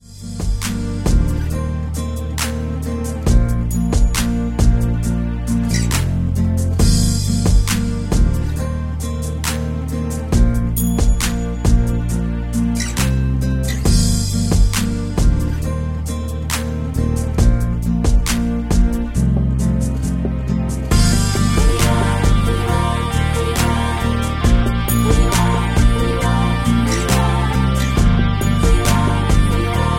Backing track files: 2010s (1044)
Buy With Backing Vocals.
Buy With Lead vocal (to learn the song).